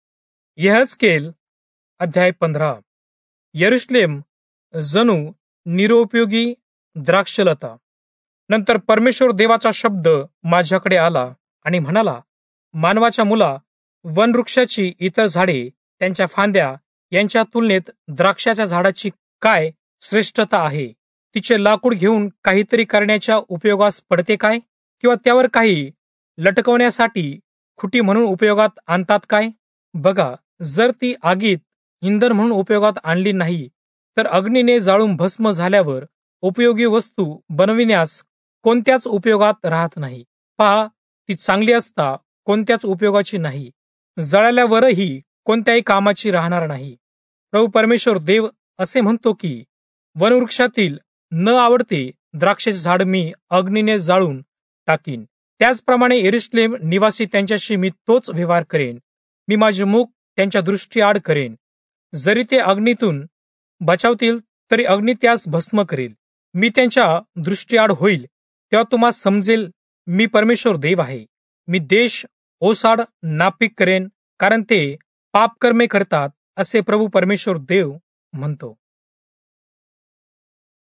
Marathi Audio Bible - Ezekiel 6 in Irvmr bible version